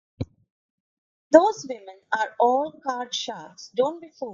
Pronounced as (IPA) /fuːld/